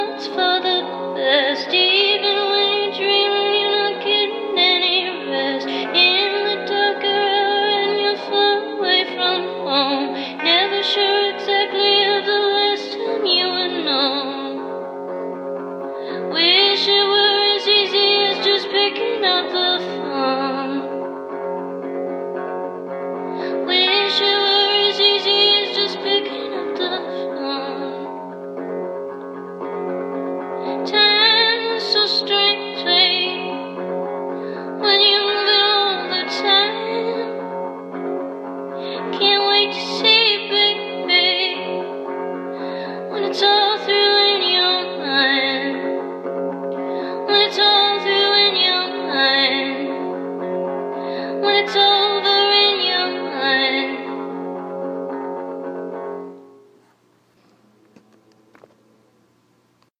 Sozinha, com a sua guitarra
pop/rock